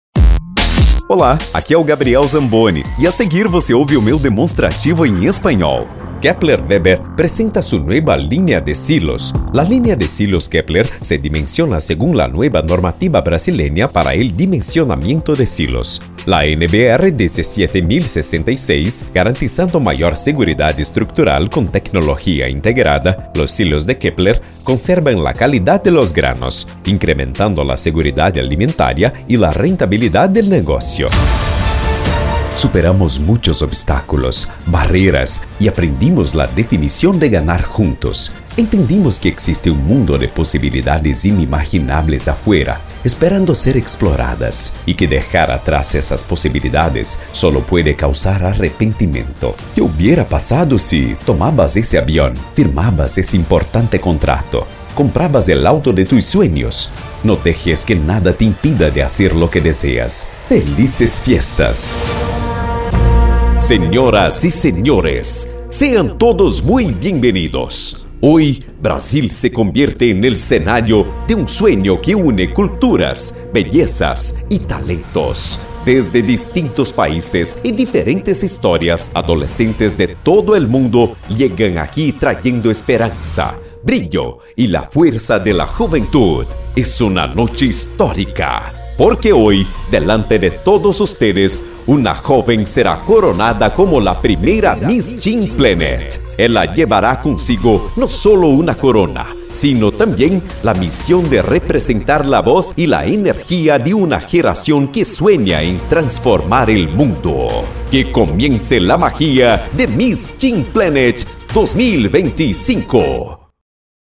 LOCUÇÃO EM ESPANHOL: